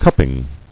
[cup·ping]